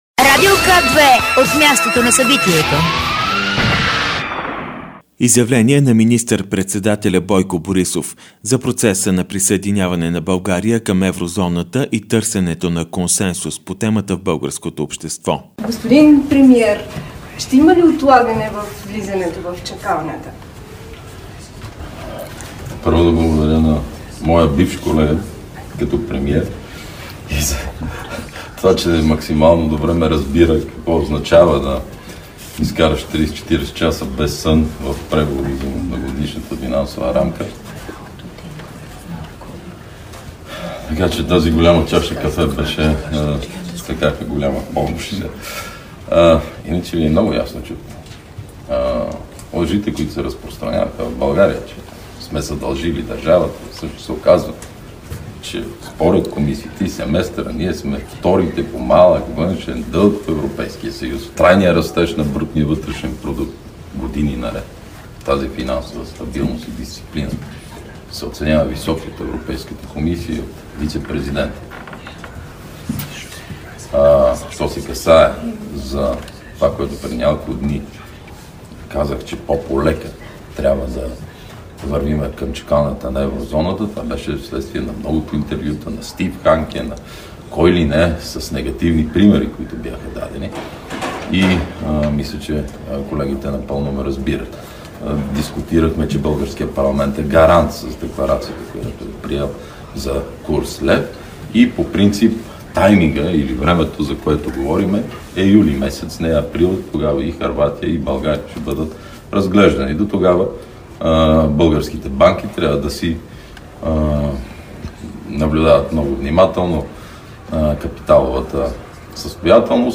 14.30 - Брифинг на министъра на вътрешните работи Млaден Маринов - директно от мястото на събитието (Народното събрание)
Радио К2 директно от мястото на събитието